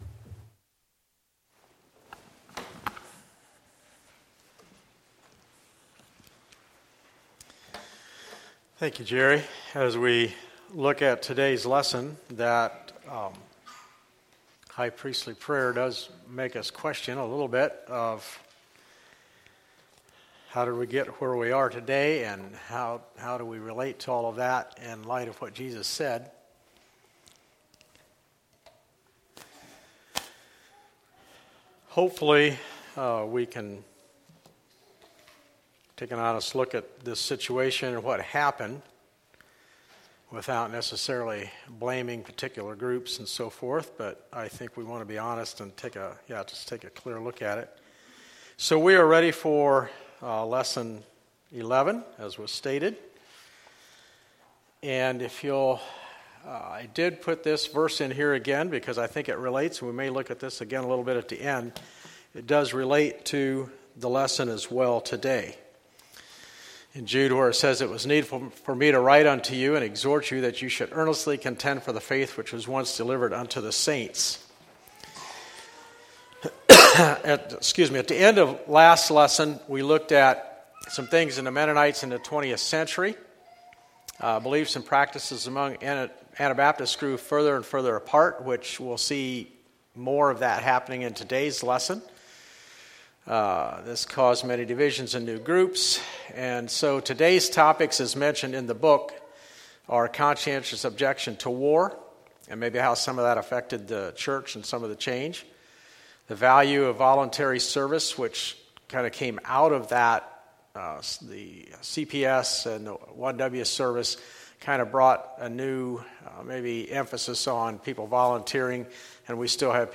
Like this sermon?